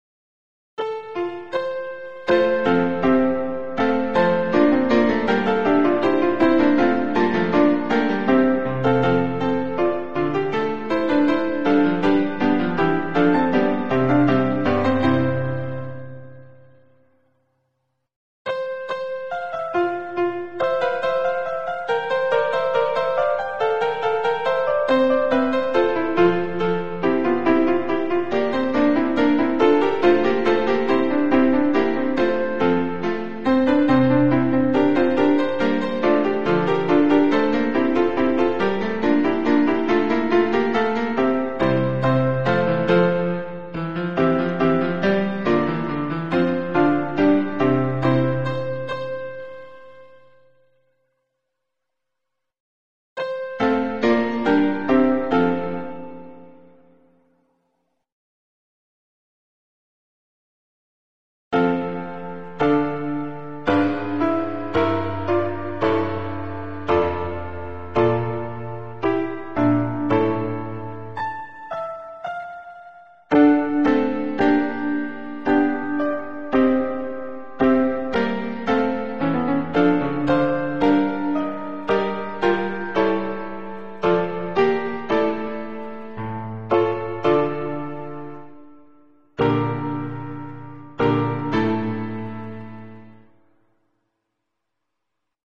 MIDI
Sans paroles
(4V : chœur égal)